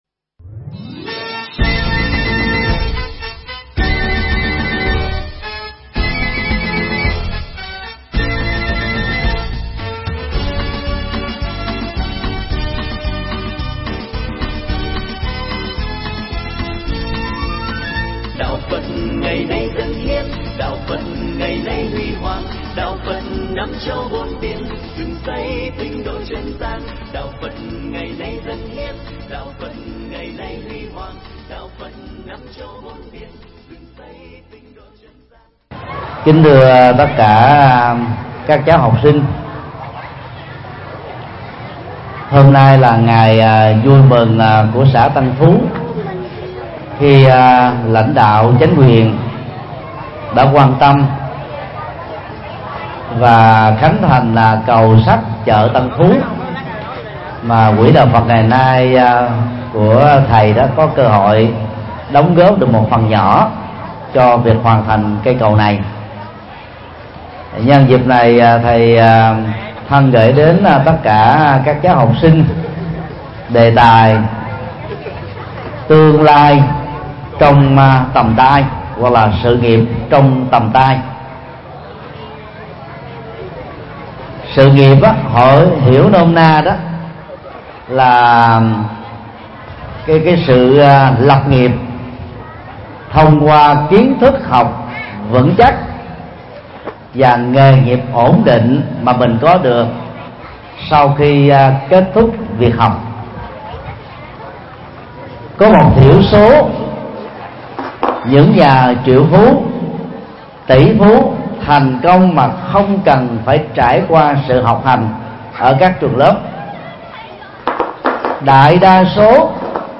Mp3 Pháp Thoại Sự nghiệp trong tầm tay - Thầy Thích Nhật Từ Giảng cho học sinh Trường THCS Tân Phú A, Trường tiểu học Tân Phú A và Tân Phú B, Bến Tre